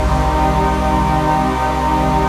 RAVEPAD 08-LR.wav